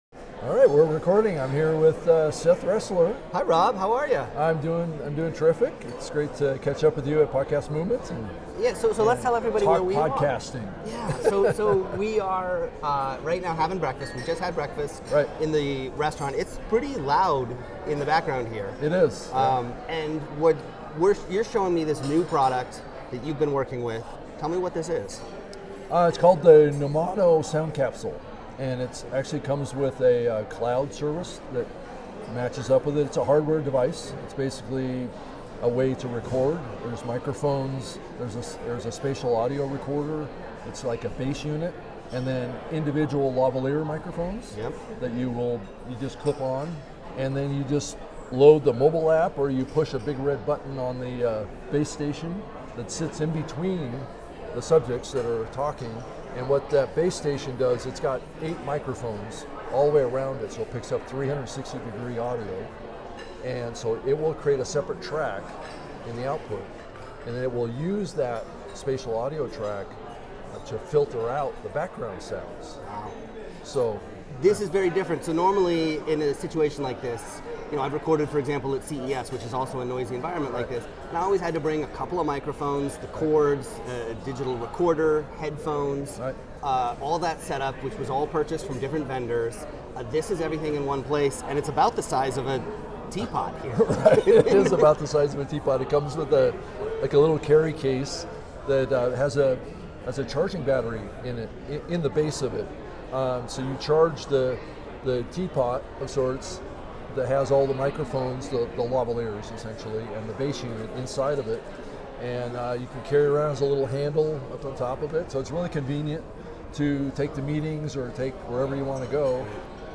It features four lavalier microphones, a digital recorder with 8 more mics built in, and a charging base.